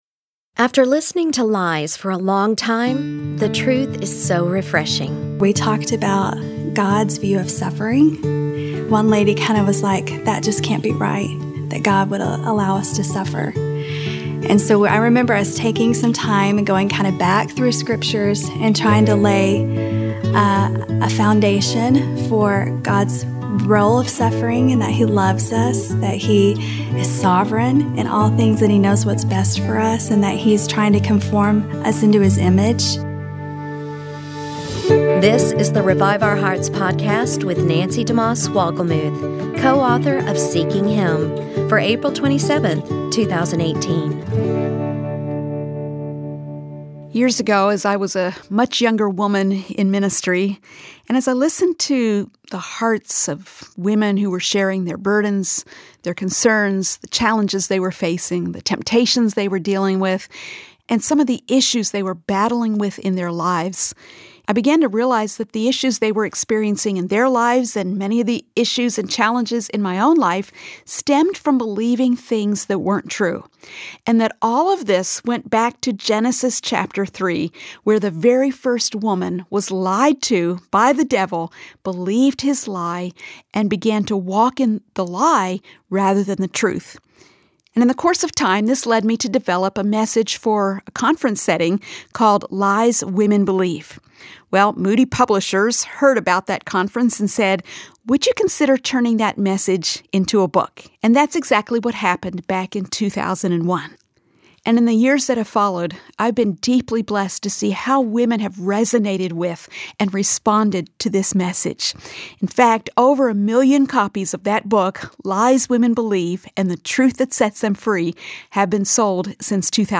We live in a generation that doubts we can ever really know the truth. We’ll hear several women who have come face to face with the truth . . . and have been changed by it!